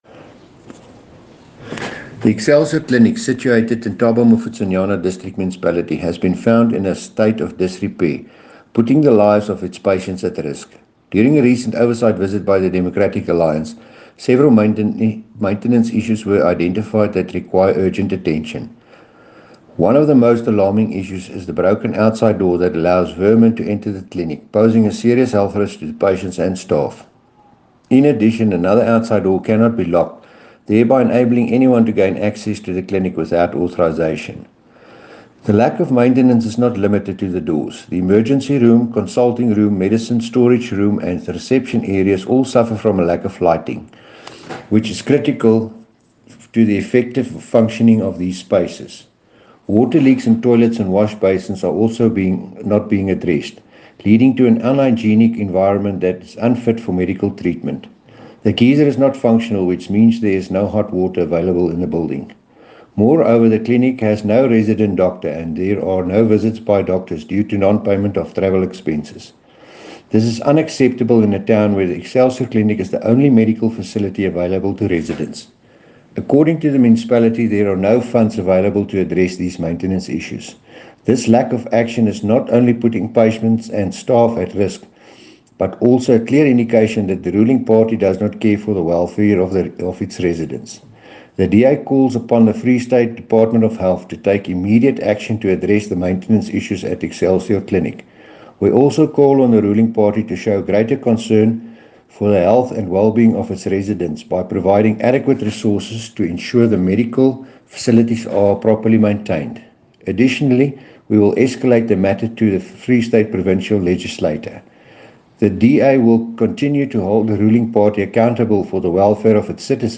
English soundbite by Cllr Dewald Hattingh and